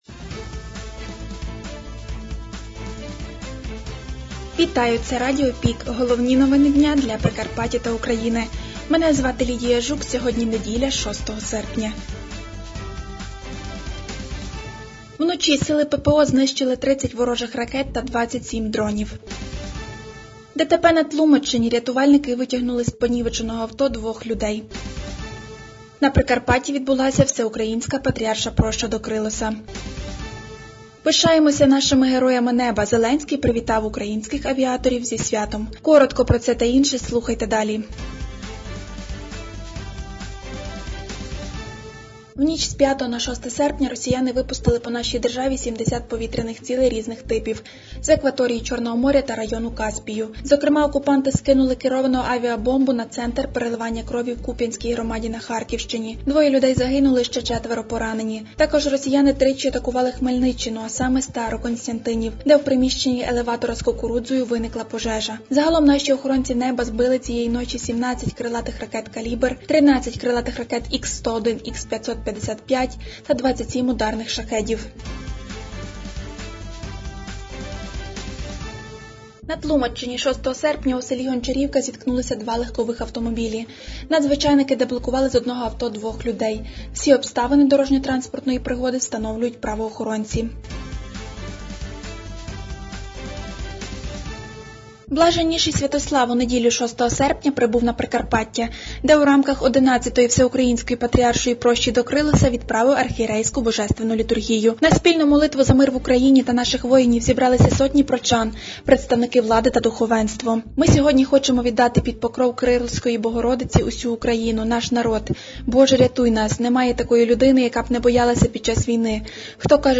Пропонуємо вам актуальне за день - у радіоформаті.